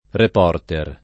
vai all'elenco alfabetico delle voci ingrandisci il carattere 100% rimpicciolisci il carattere stampa invia tramite posta elettronica codividi su Facebook reporter [ rep 0 rter ; ingl. rip 0 otë ] s. m.; inv. (pl. ingl. reporters [ rip 0 otë @ ])